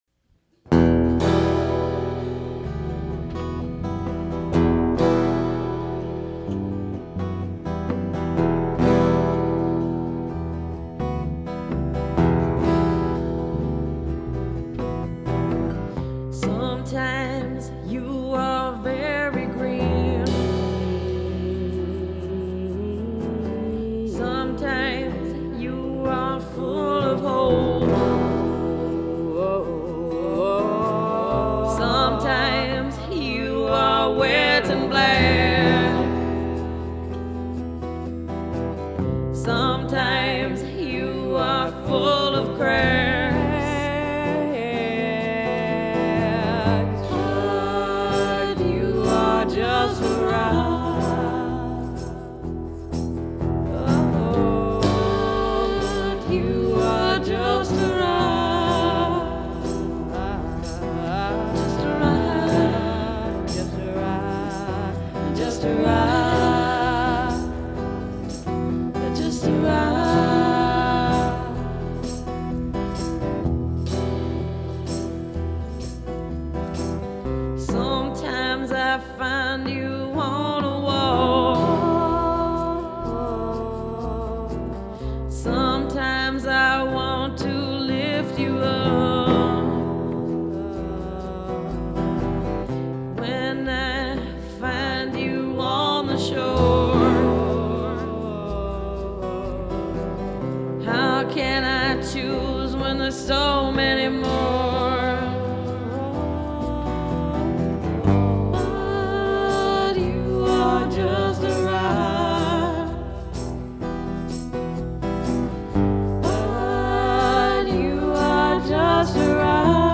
performed the same evening and never again after